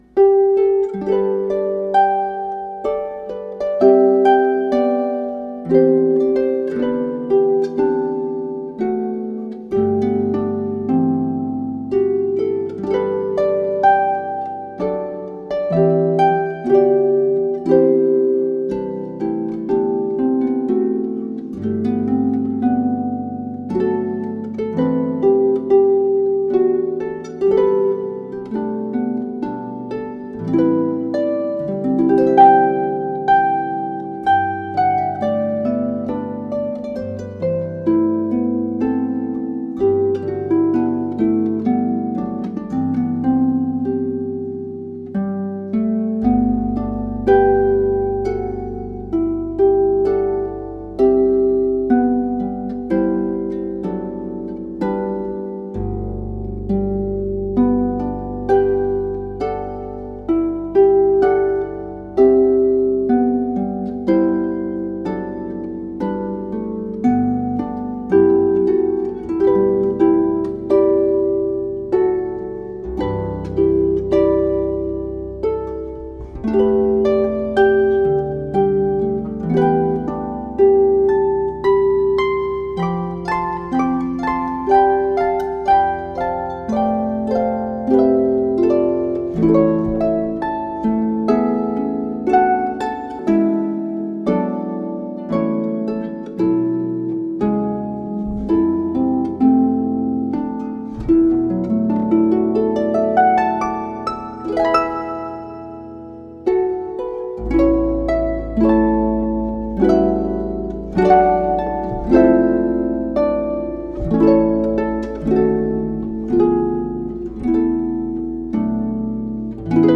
UPPER INT TO ADV PEDAL HARP
It is lush and beautiful and a joy to play.